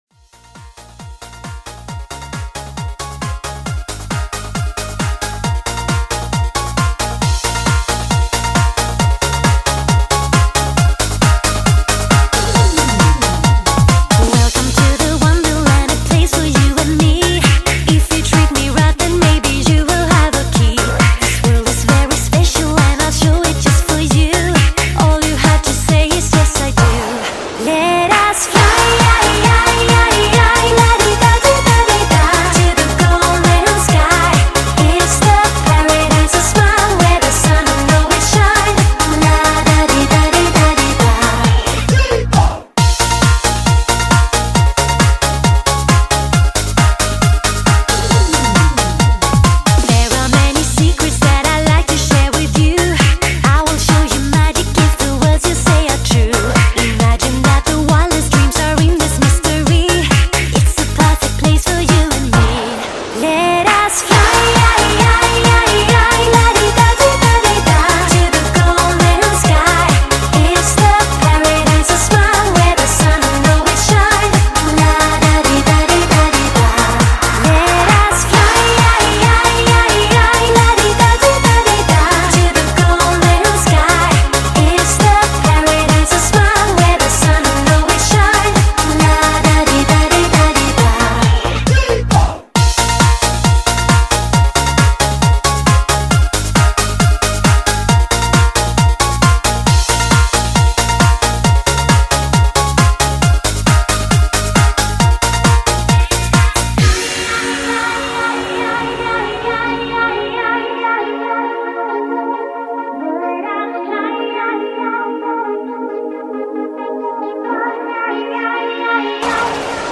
不管是摇滚、慢摇、电音还是串烧能让你疯狂就是王道